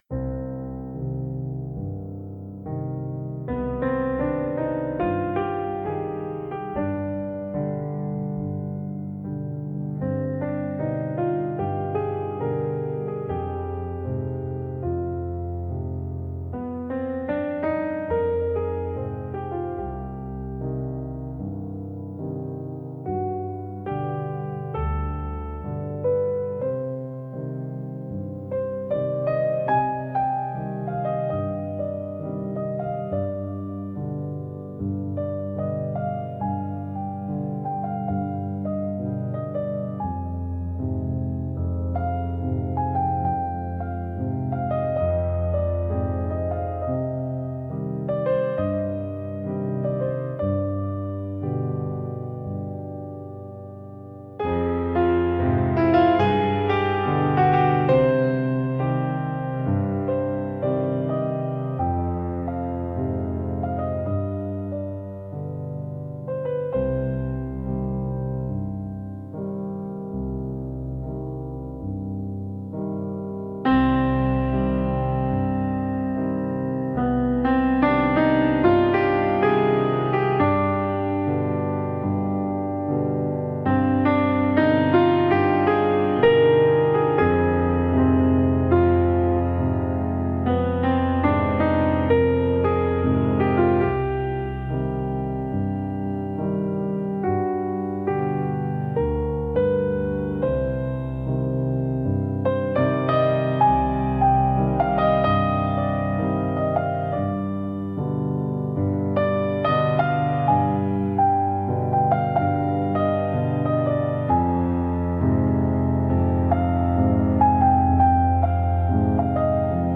Falling Shadows (piano)